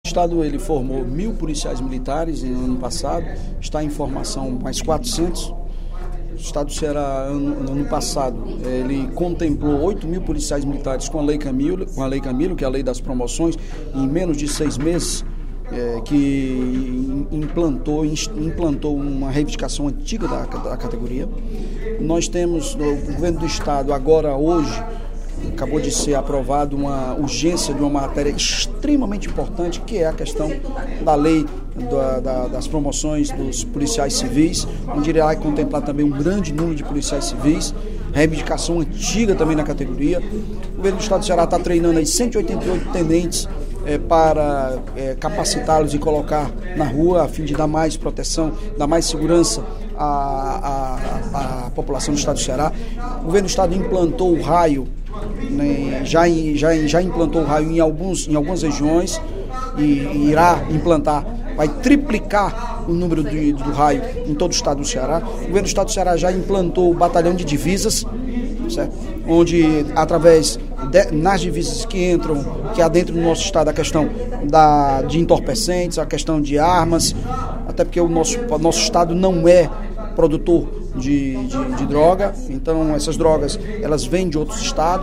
O líder do Governo na Casa, deputado Evandro Leitão (PDT), ressaltou as ações do Executivo na segurança pública, em pronunciamento no primeiro expediente da sessão plenária desta quarta-feira (09/03).